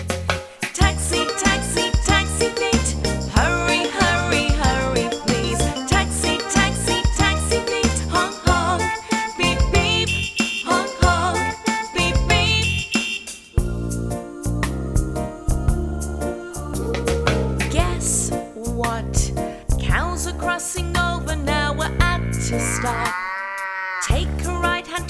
Partner song